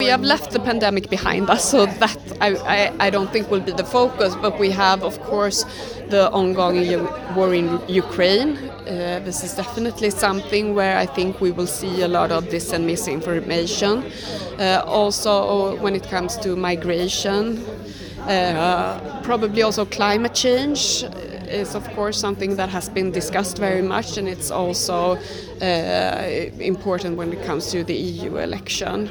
Poznata švedska novinska kolumnistica, radijska voditeljica i veleposlanica demokracije Emma Frans za Media servis se osvrnula na dezinformacije u kontekstu super izborne godine. U kontekstu nadolazećih izbora, detektirala je teme i pitanja u kojima bi se moglo naći puno dezinformacija.